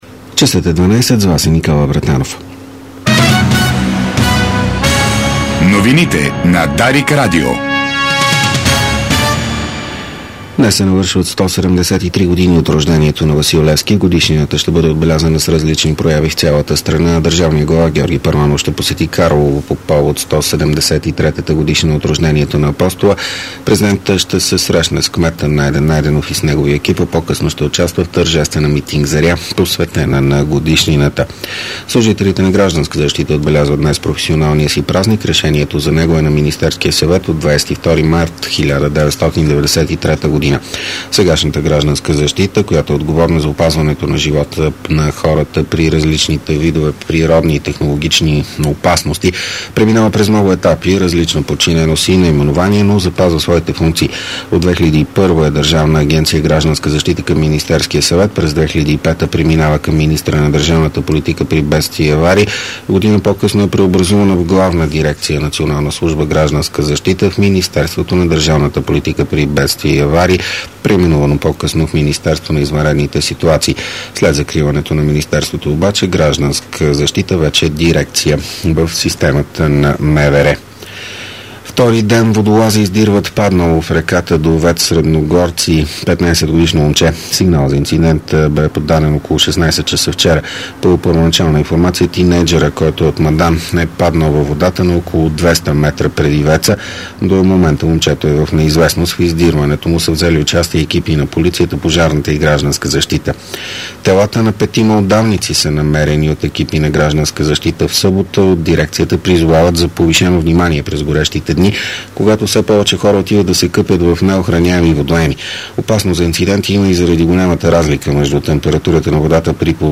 Обедна информационна емисия - 18.07.2010